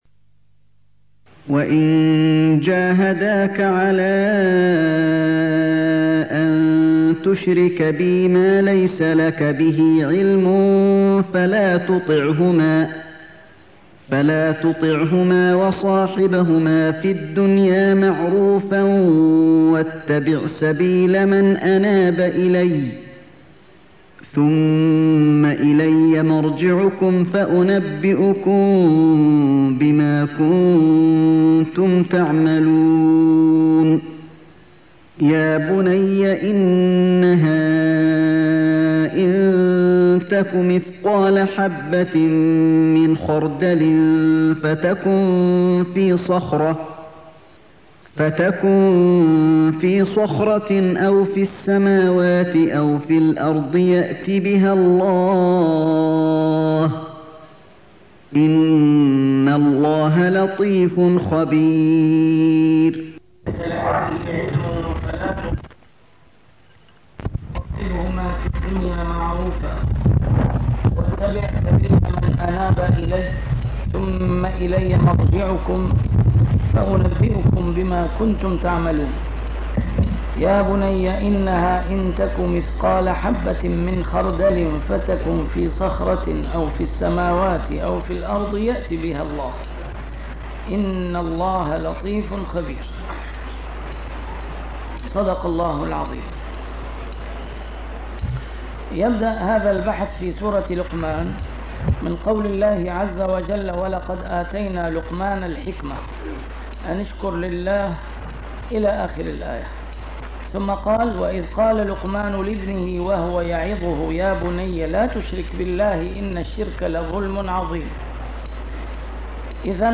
نسيم الشام › A MARTYR SCHOLAR: IMAM MUHAMMAD SAEED RAMADAN AL-BOUTI - الدروس العلمية - تفسير القرآن الكريم - تسجيل قديم - الدرس 341: لقمان 15
تفسير القرآن الكريم - تسجيل قديم - A MARTYR SCHOLAR: IMAM MUHAMMAD SAEED RAMADAN AL-BOUTI - الدروس العلمية - علوم القرآن الكريم - الدرس 341: لقمان 15